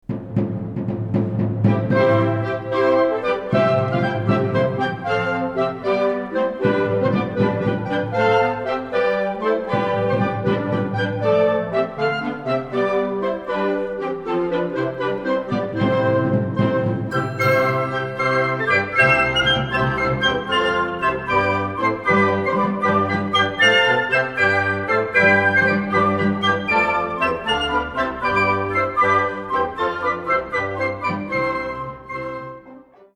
Kategorie Blasorchester/HaFaBra
Besetzung Ha (Blasorchester)